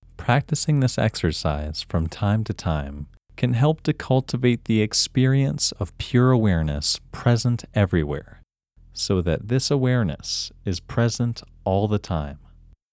WHOLENESS English Male 20
WHOLENESS-English-Male-20.mp3